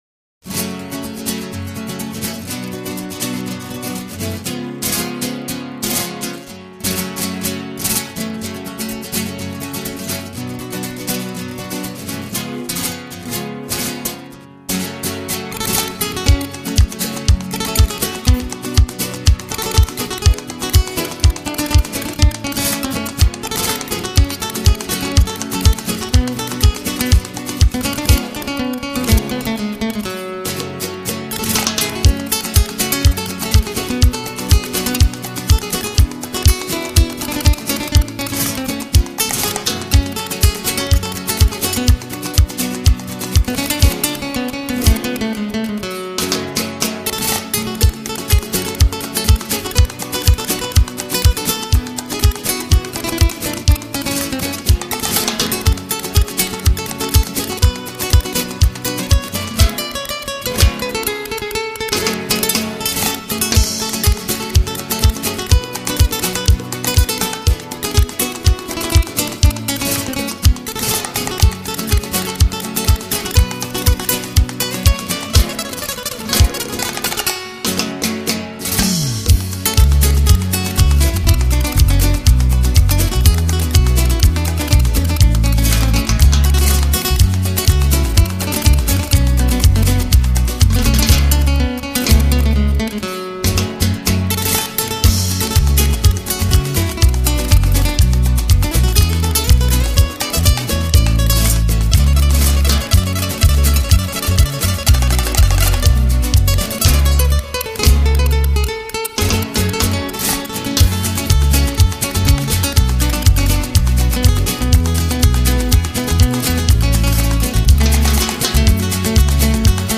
【新世纪吉他】
音乐风格: Latin / Flamenko / Instrumental